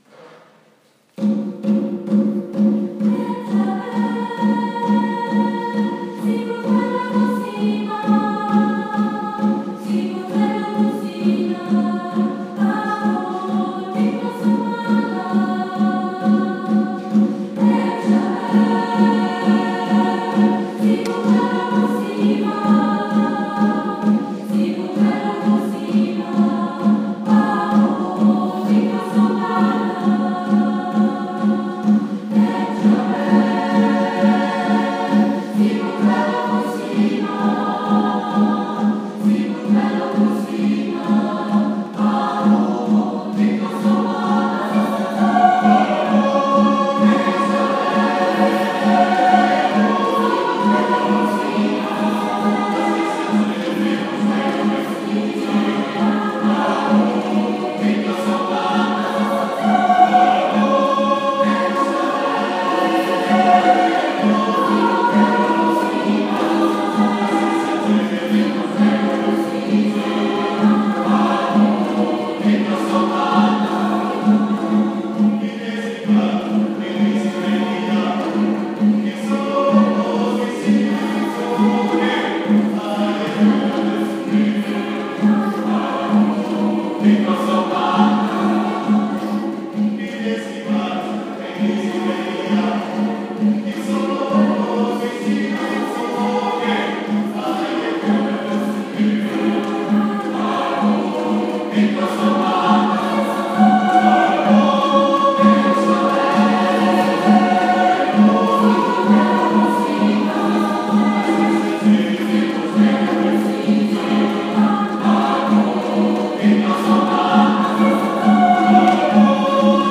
Kirchenchor Sigharting
Zahlreiche Frauen und auch einige Männer verschönern mit ihrem Gesang nicht nur den wöchentlichen Sonntagsgottesdienst, sondern auch viele kirchliche Feste und Feiern.